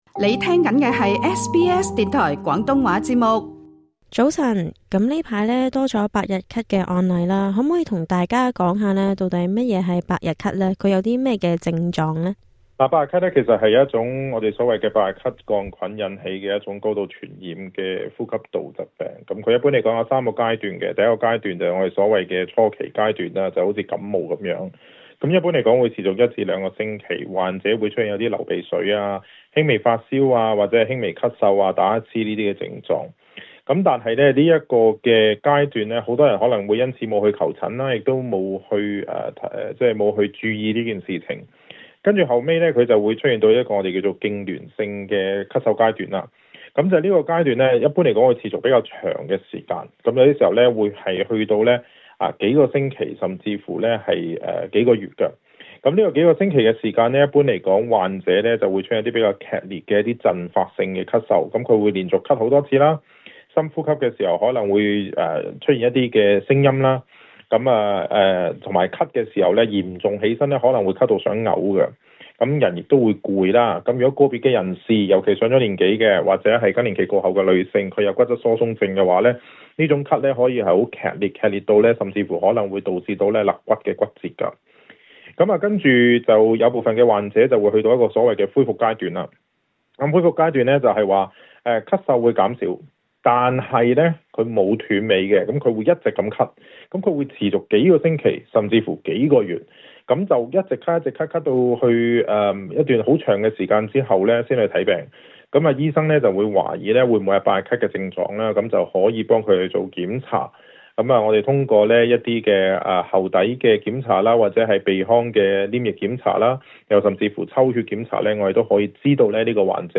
瀏覽更多最新時事資訊，請登上 廣東話節目 Facebook 專頁 、 MeWe 專頁 、 Twitter 專頁 ，或訂閱 廣東話節目 Telegram 頻道 。